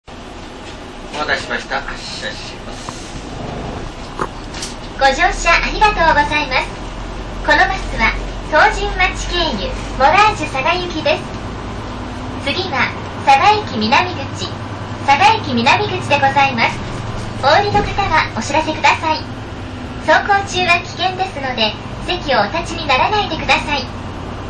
車内放送の声も可愛くて感情がこもっていて最高ッ！